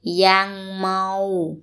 – yang – mau